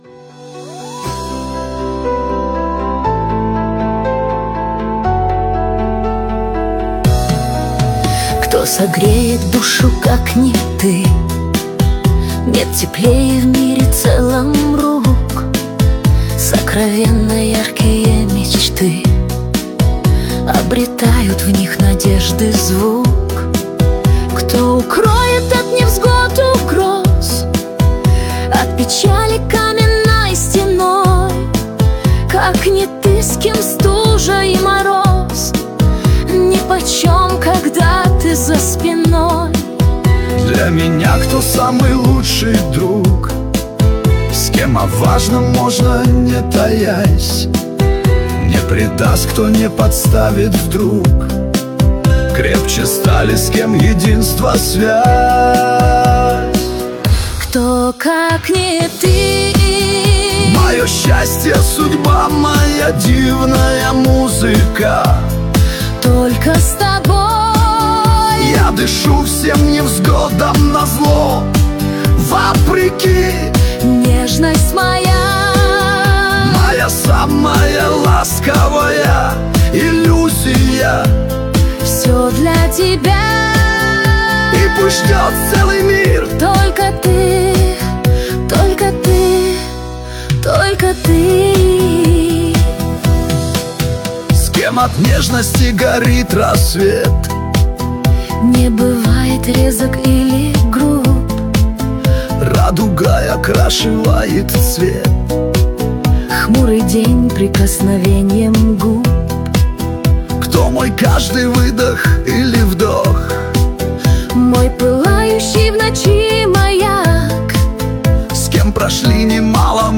Жанр: Новинки русской музыки